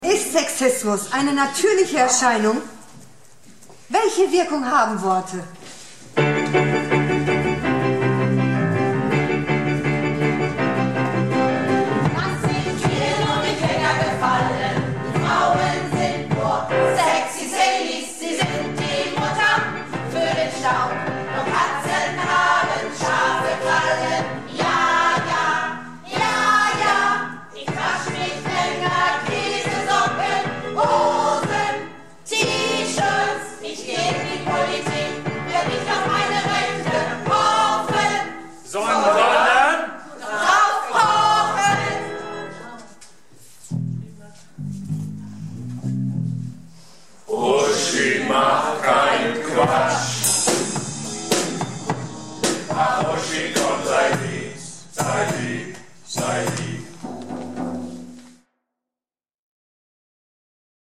Projektchor "Keine Wahl ist keine Wahl" - Theaterprobe 28.09.19